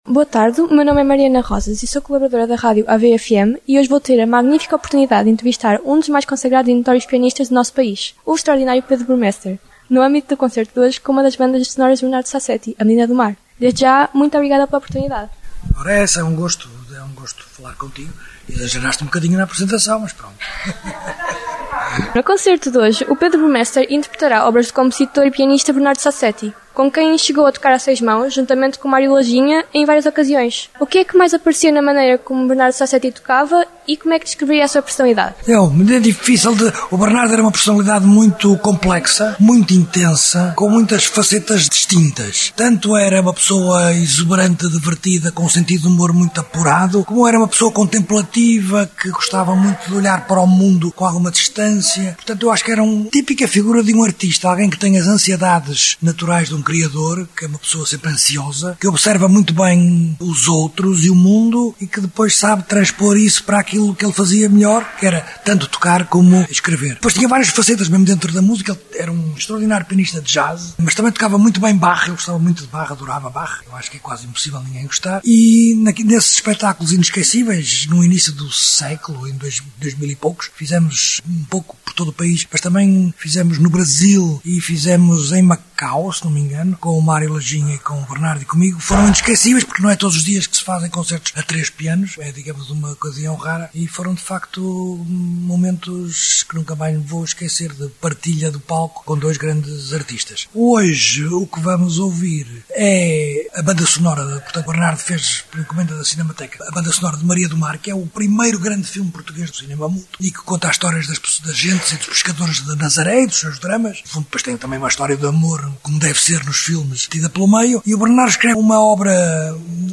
Fica disponível, no player em baixo, uma entrevista a Pedro Burmester no âmbito deste concerto.